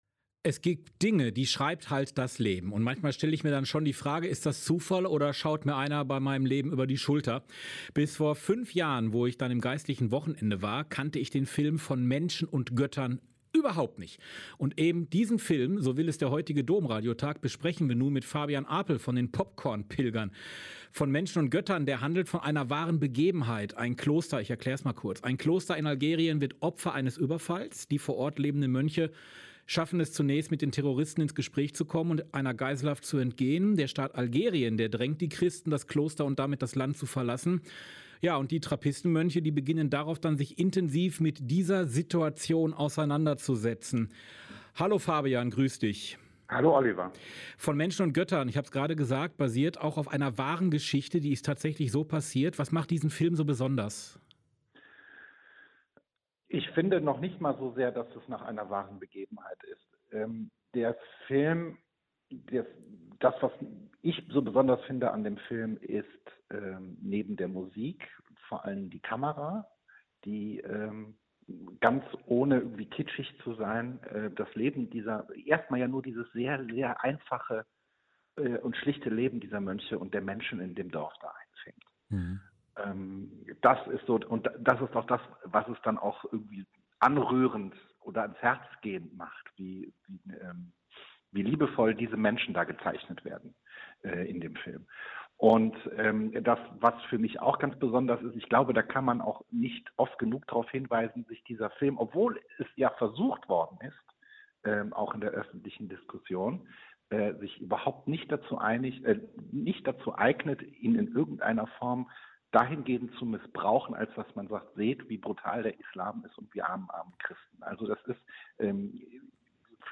Ein Interview mit Ein Interview mit